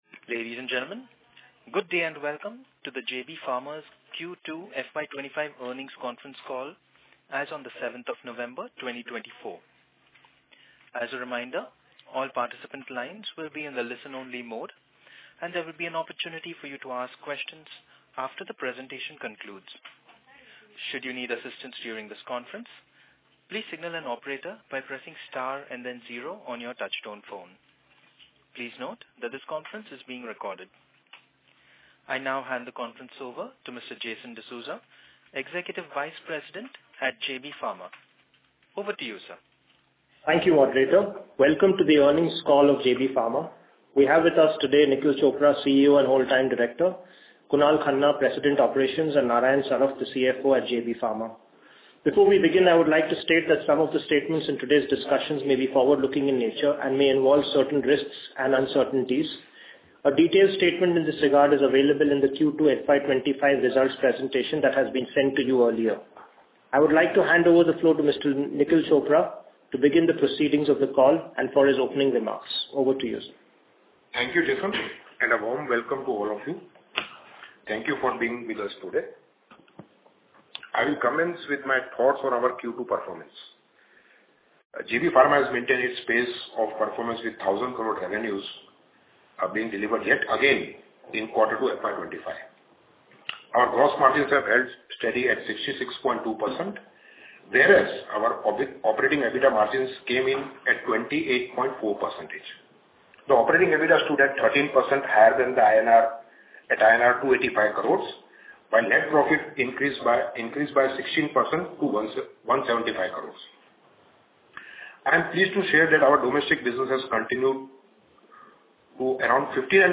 Q2FY25 JB Pharma Earnings Call